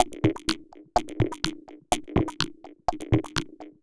tx_perc_125_clockwerk2.wav